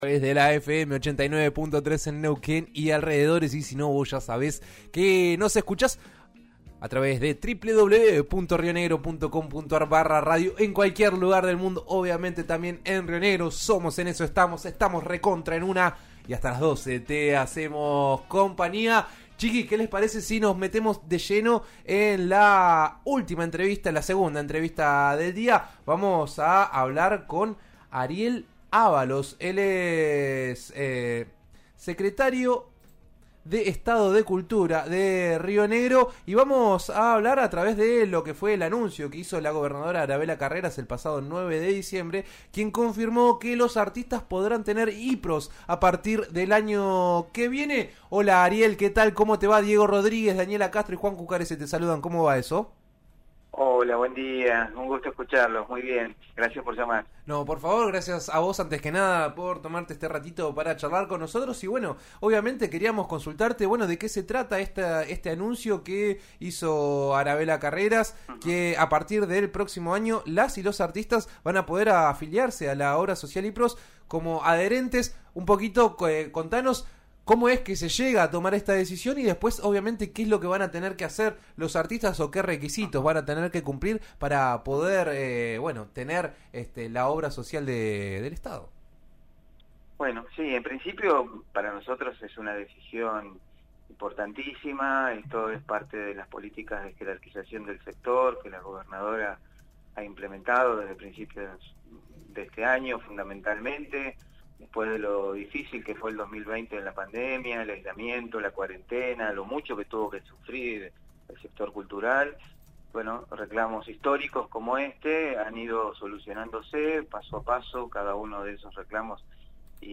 Ariel Avalos, Secretario de Estado de Cultura de Río Negro, comentó al aire de «En Eso Estamos», por RN RADIO (89.3 en Neuquén) que los artistas recibirán la obra social como parte de un plan de jerarquización del sector.